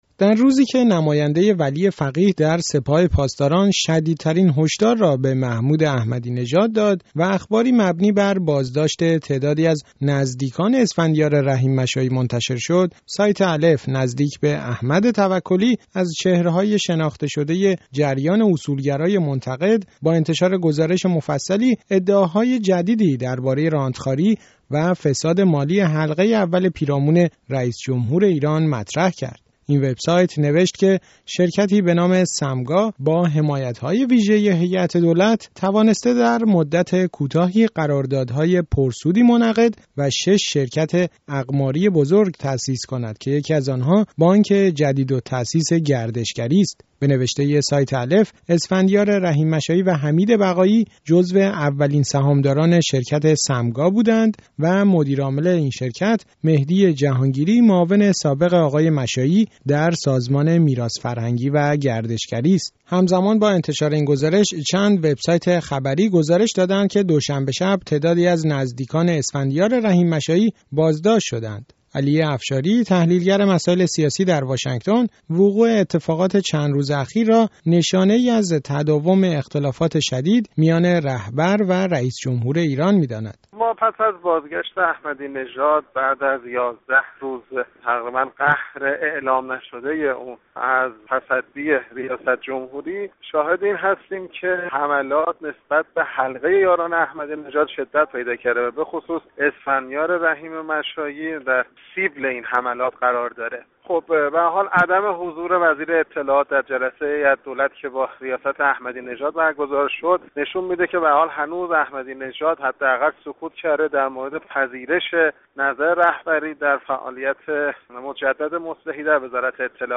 گفت وگو